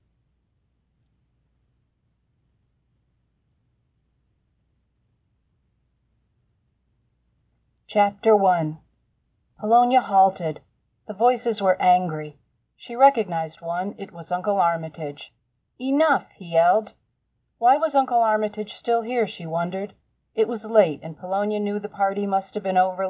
This is the best I can do on my Android, tweaked in Audacity.